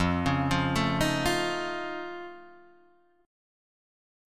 F+7 chord